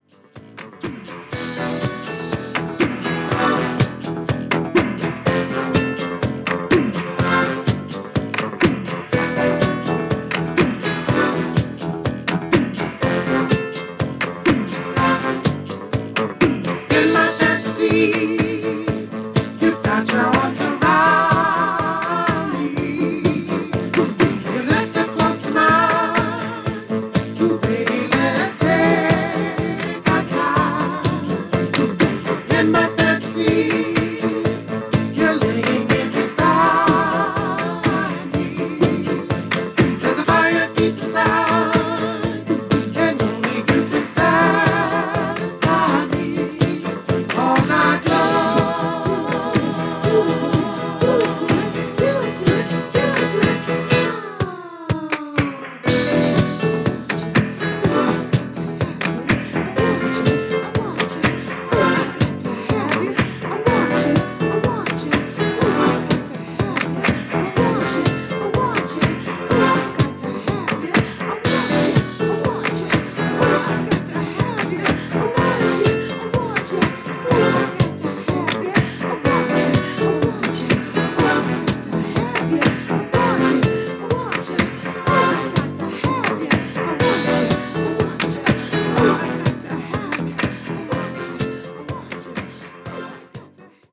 San Francisco・War Memorial Opera House での２枚組ライブ盤です。